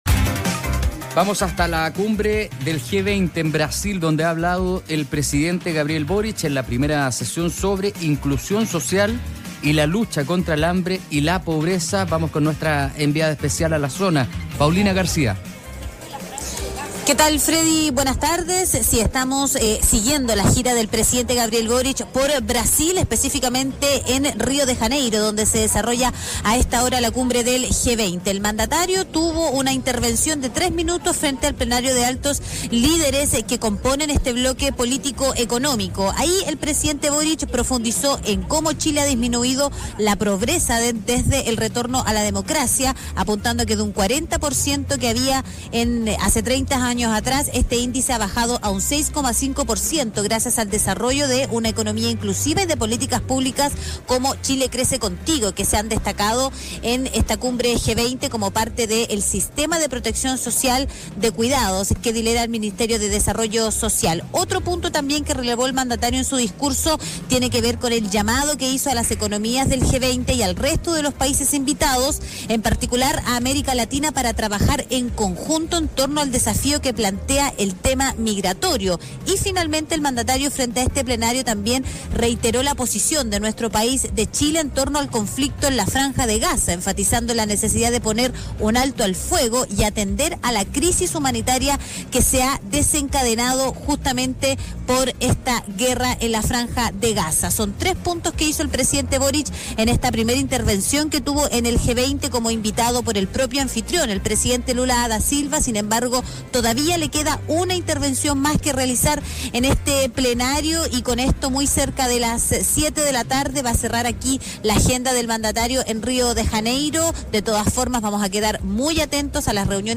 Este lunes, en su intervención en la primera sesión de la Cumbre del G20, celebrada en Río de Janeiro, el Presidente Gabriel Boric instó a las principales economías del mundo a enfrentar de manera conjunta desafíos como la desigualdad, la migración y la seguridad alimentaria.